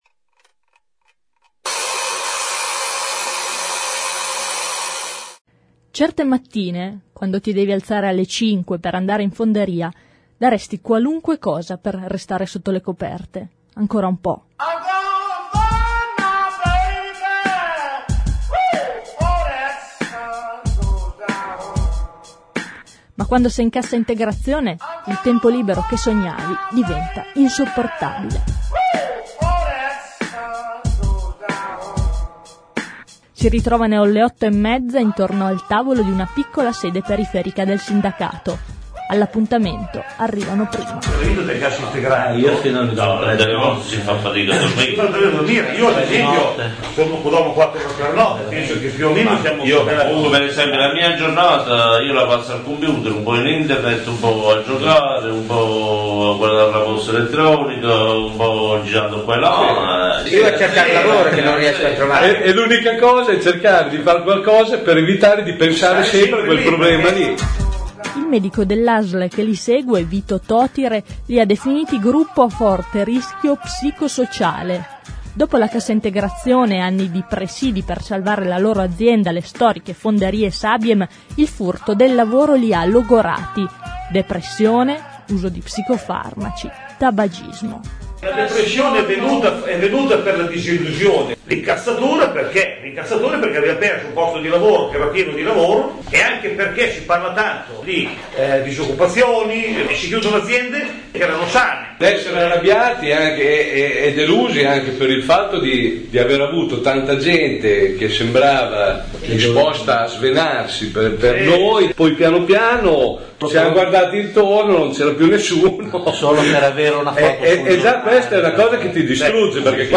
Li abbiamo incontrati ad una delle loro riunioni